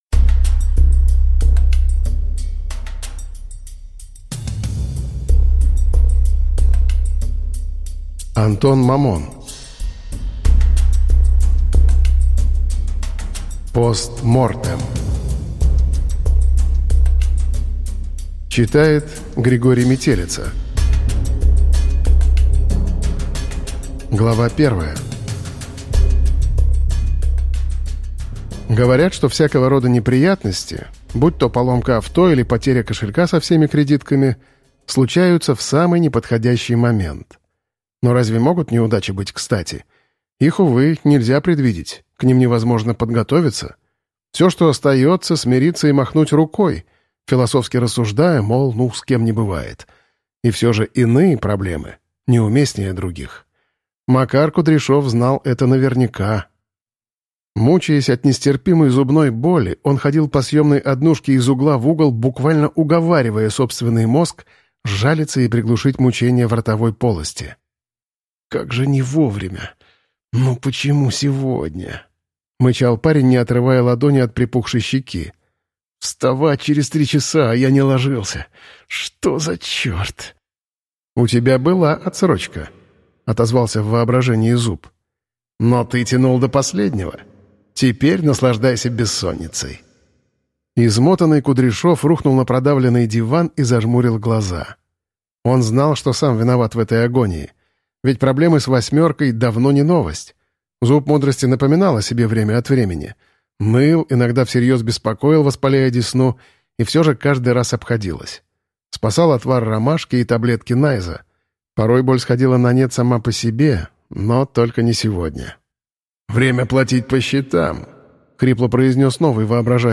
Аудиокнига Post Mortem | Библиотека аудиокниг